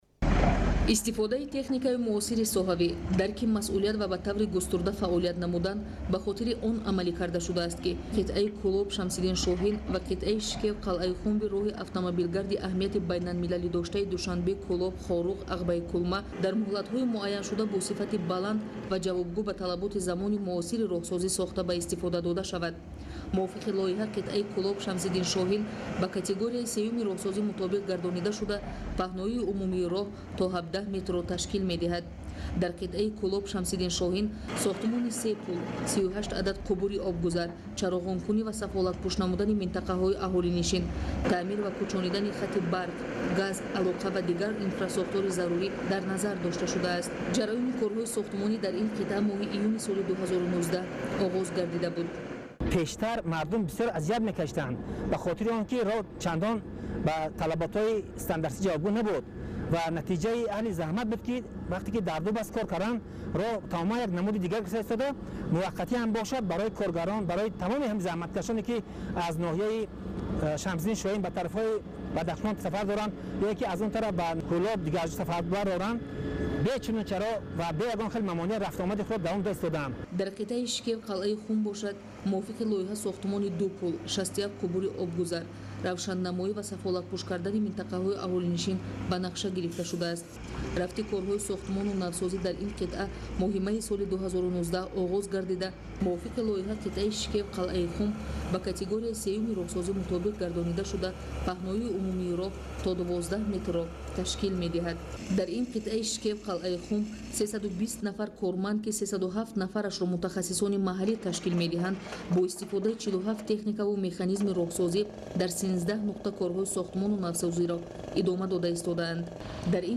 راه کولاب –شمس الدین شاهین در حال بهسازی است قراراست عرض جاده 17متر پهن شود شنوده گزارشی